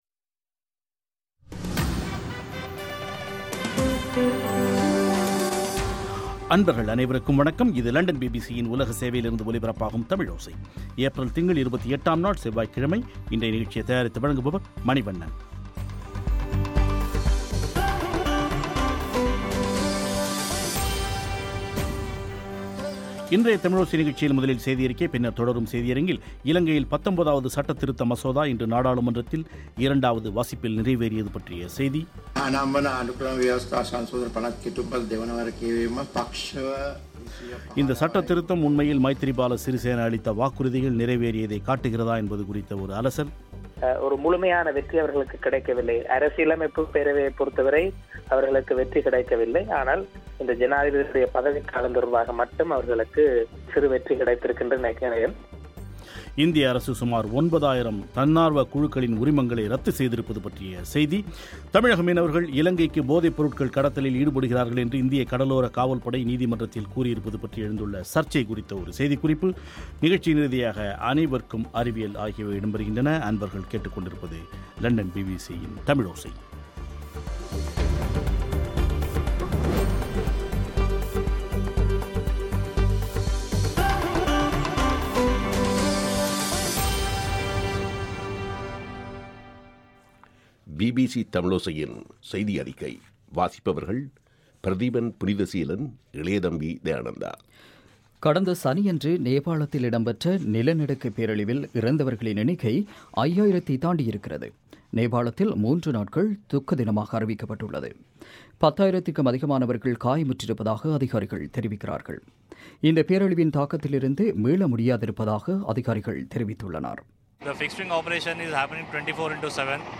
இந்த சட்டத்திருத்தம் உண்மையில் மைத்திரபால சிறிசேன அளித்த வாக்குறுதிகள் நிறைவேறியதைக் காட்டுகிறதா என்பது குறித்த ஒரு பேட்டி